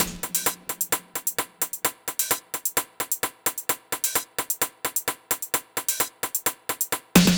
Sf Hats Loop.wav